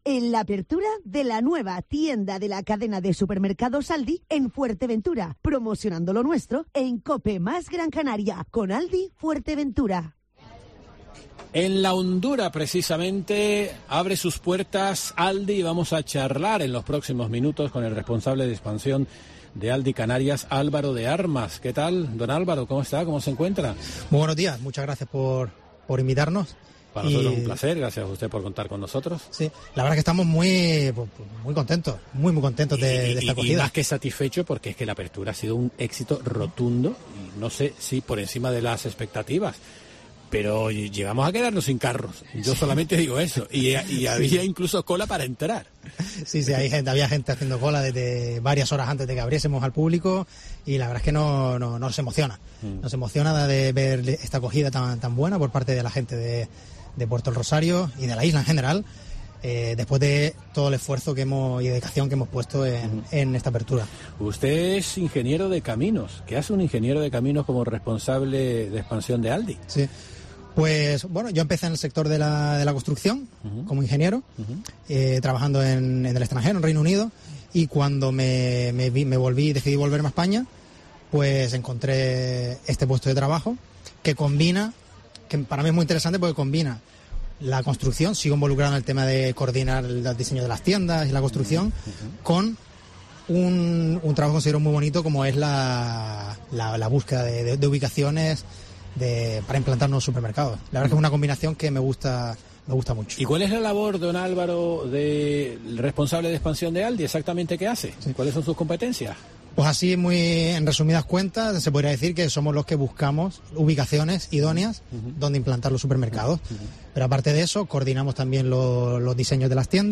La Mañana de COPE Gran Canaria se ha desplazado a Fuerteventura para emitir la apertura del primer establecimiento de ALDI en una isla no capitalina de Canarias.